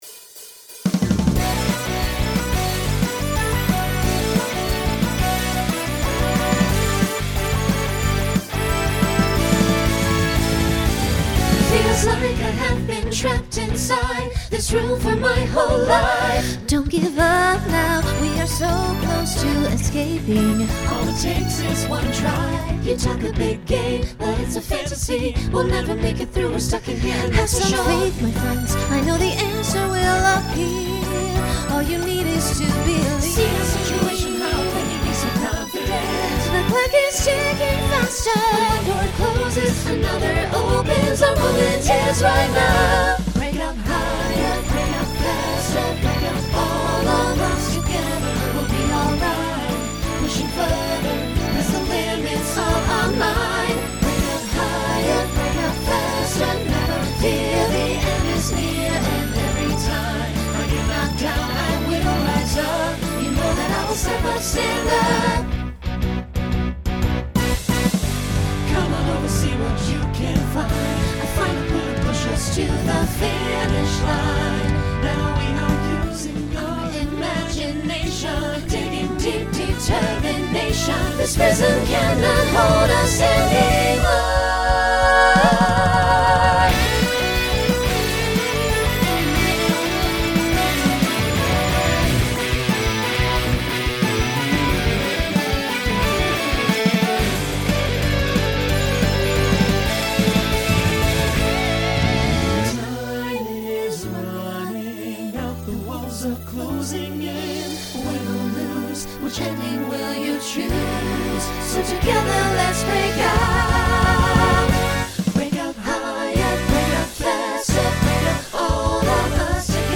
Genre Pop/Dance , Rock Instrumental combo
Story/Theme Voicing SATB